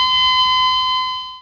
flatLineSound.wav